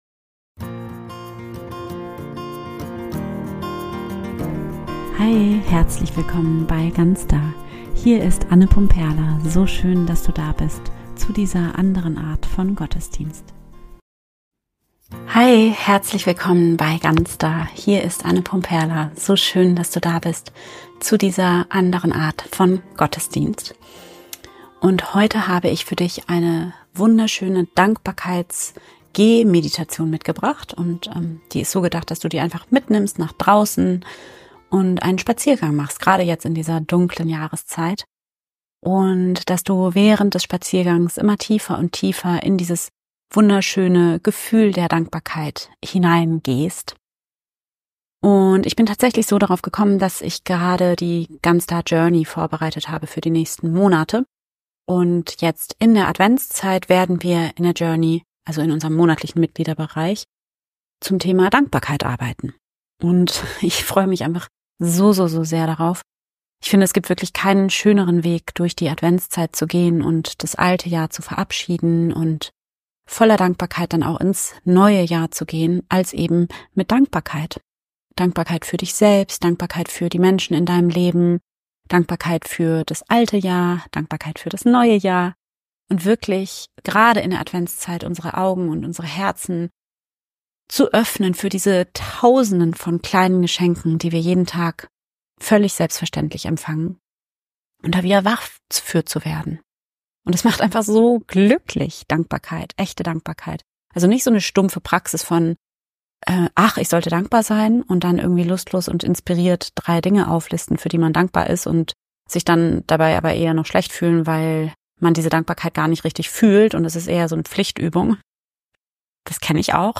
Heute habe ich dir eine wunderschöne Dankbarkeits-Gehmeditation mitgebracht.